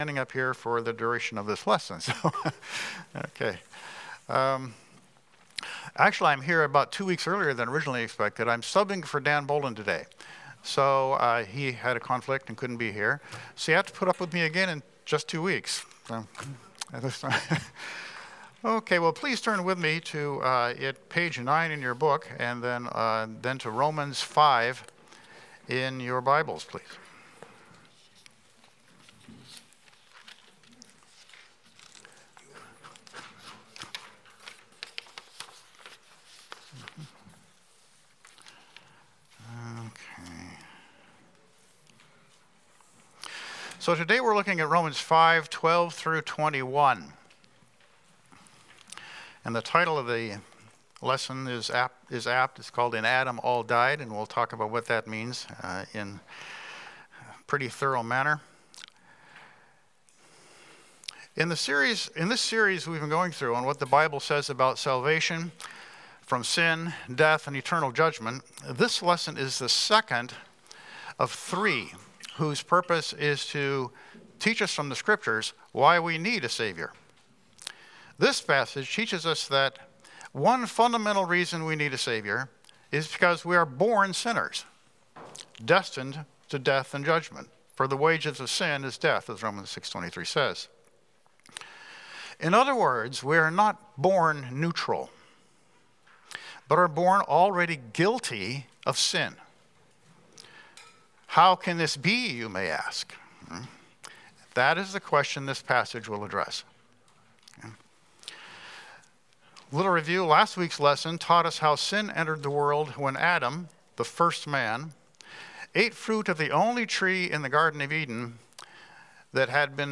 Romans 5:12-21 Service Type: Sunday School Sin and death entered the human race through Adam.